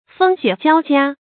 風雪交加 注音： ㄈㄥ ㄒㄩㄝˇ ㄐㄧㄠ ㄐㄧㄚ 讀音讀法： 意思解釋： 風和雪同時襲來。